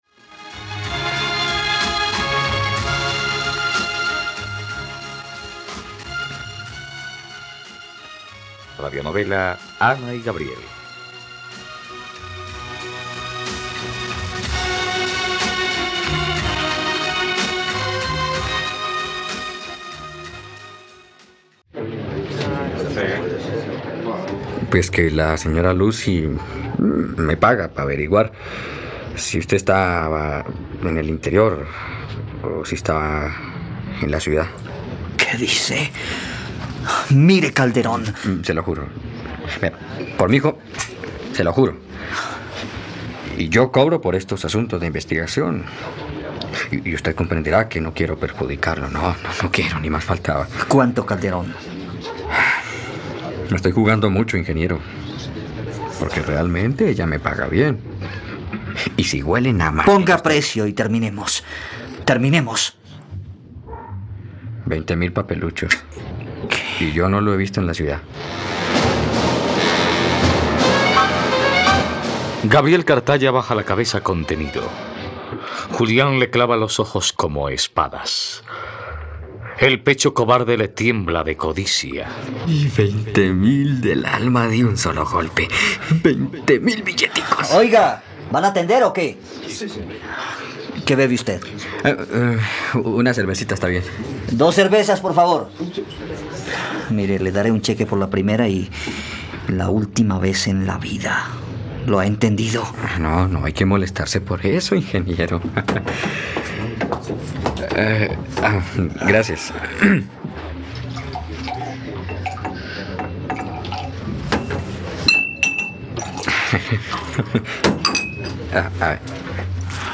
..Radionovela. Escucha ahora el capítulo 75 de la historia de amor de Ana y Gabriel en la plataforma de streaming de los colombianos: RTVCPlay.